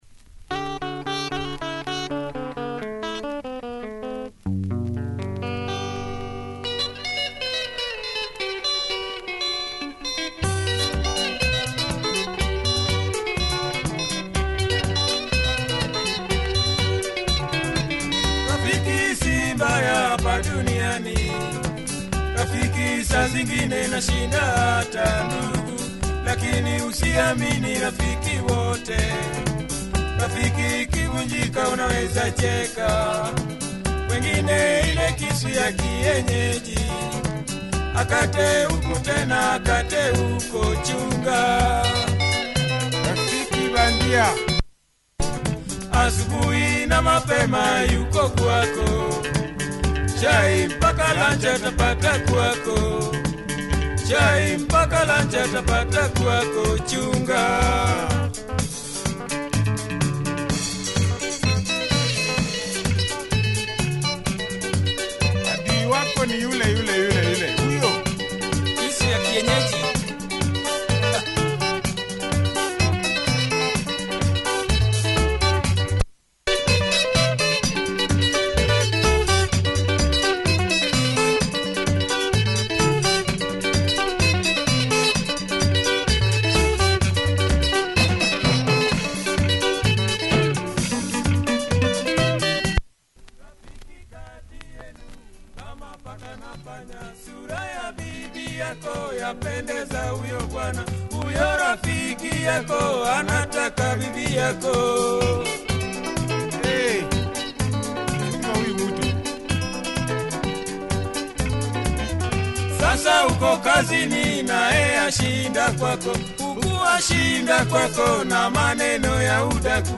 Nice luo benga by this group team-up